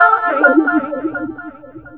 VOX FX 7  -L.wav